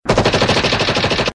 • MACHINE GUN FIRING.mp3
machine_gun_firing_qjx.wav